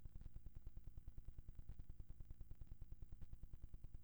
Störungen bei Verstärkung von Signalaufnahmen mit piezokeramischen Element
Wenn das ganze jedoch in einem anderen Raum anbringe, kommt es unregelmäßig zu Ausfällen des Systems. Das äußert sich wie im angehängten .wav File.